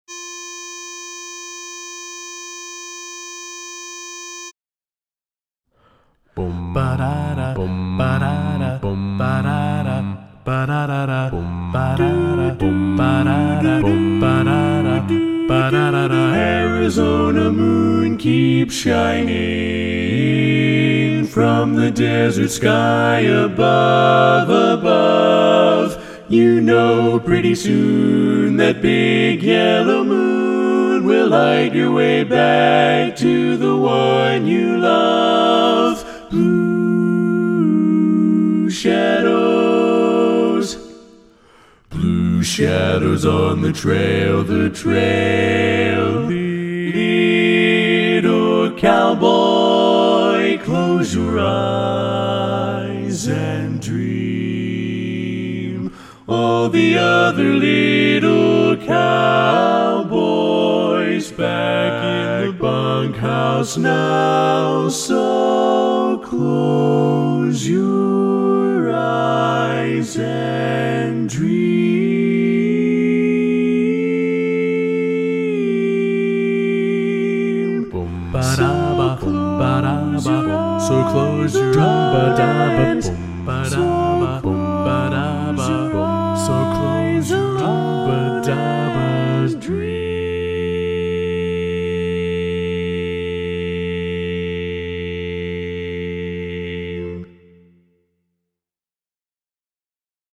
Minus Lead   Bari Pred